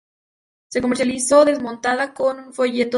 mon‧ta‧je
/monˈtaxe/